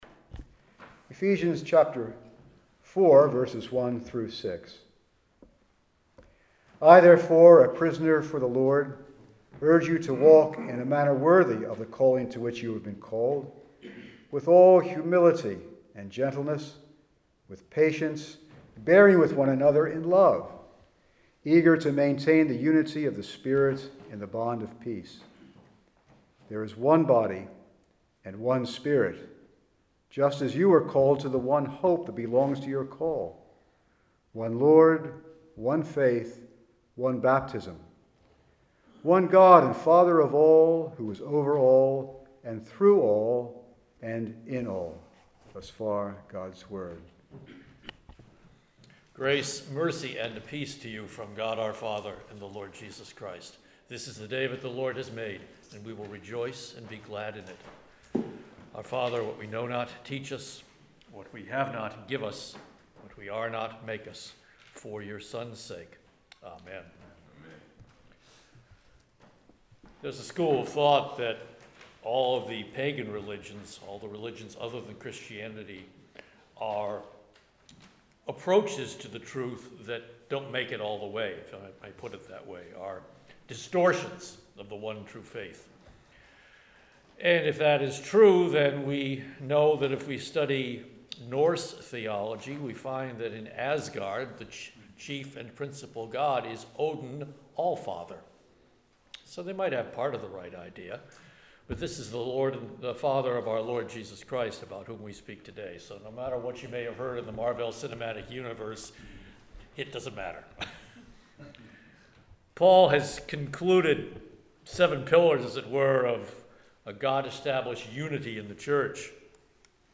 The Cornerstone Church - Sermons